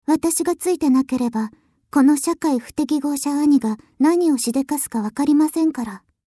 みさきサンプルボイス@　みさきサンプルボイスA　みさきサンプルボイスB 　私服差分